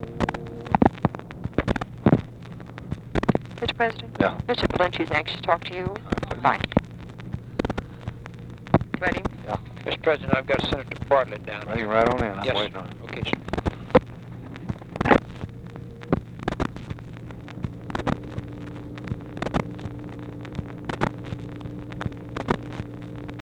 Conversation with JACK VALENTI and TELEPHONE OPERATOR
Secret White House Tapes